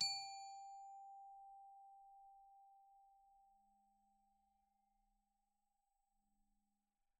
glock_medium_G4.wav